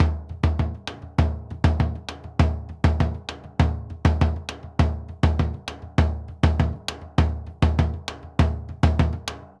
Bombo_Baion_100_3.wav